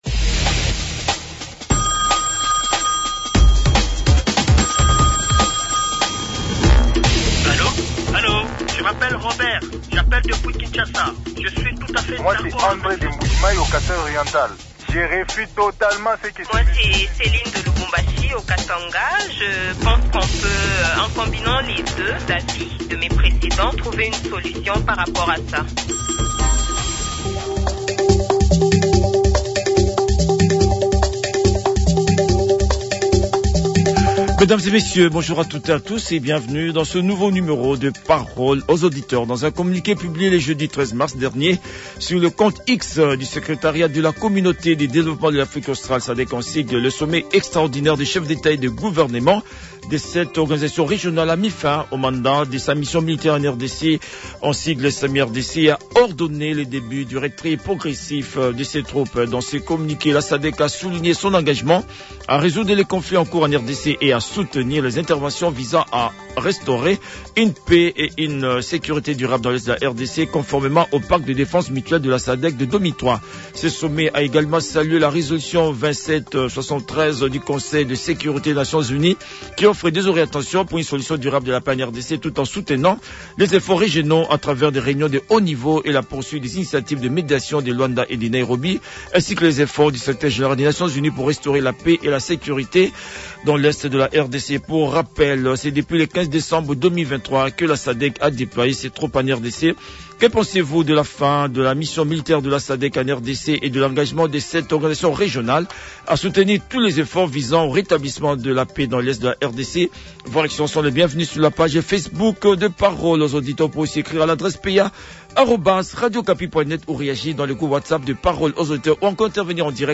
Les auditeurs ont débattu avec l’Honorable Joseph Nkoy, rapporteur adjoint de la Commission Défense et Sécurité de l’Assemblée nationale